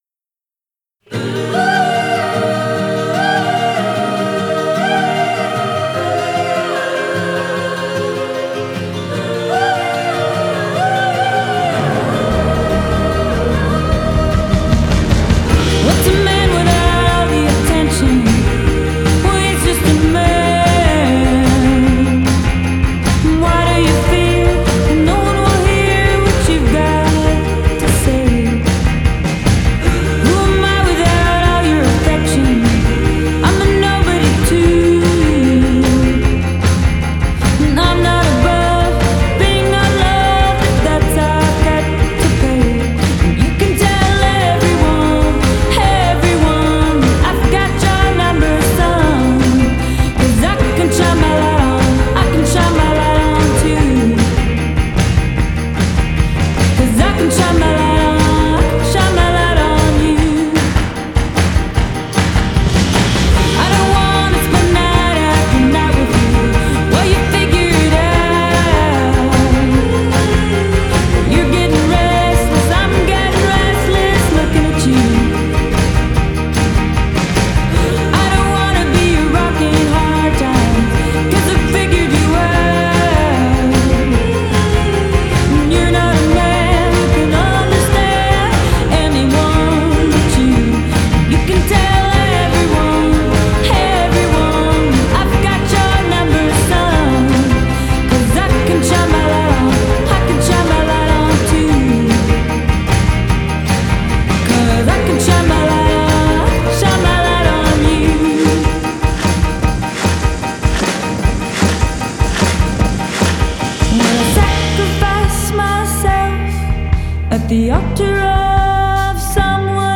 Genre: Indie Pop, Twee Pop